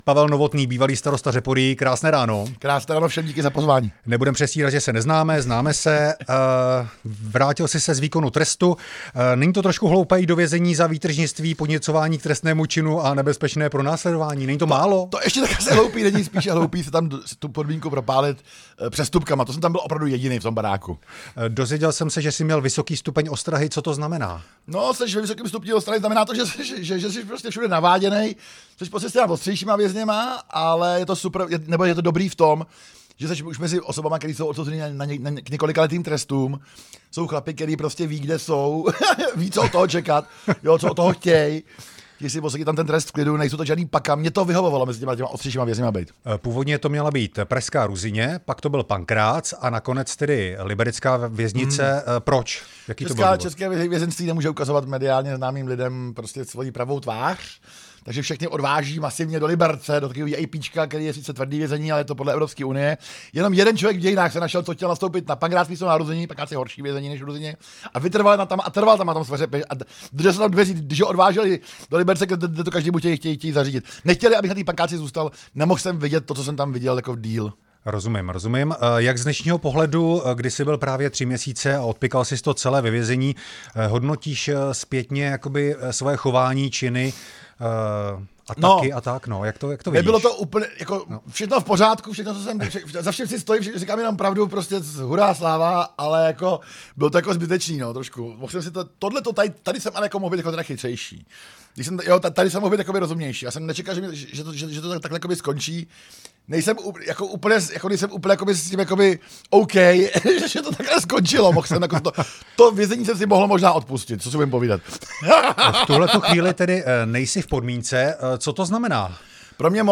Rozhovor bývalým starostou Řeporyjí Pavlem Novotným